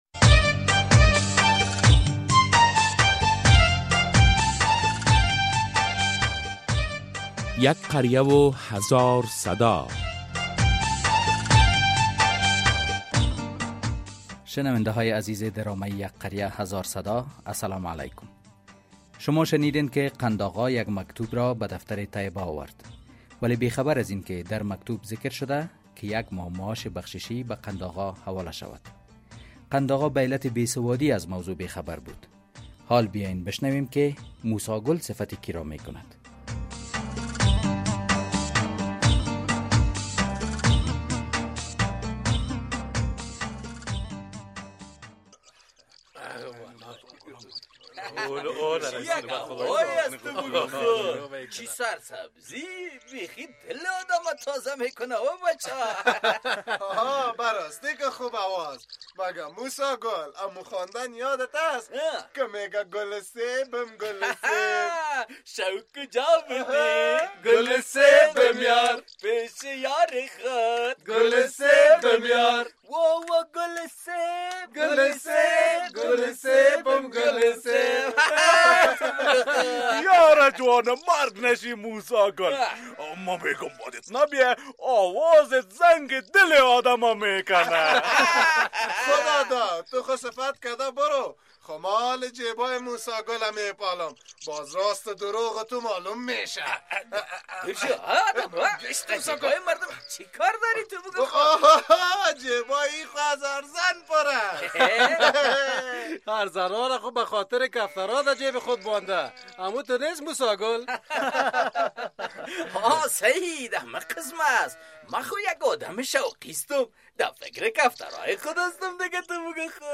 این موضوع بحث زنده این هفته قسمت ۲۴۱م درامه یک قریه هزار صدا ...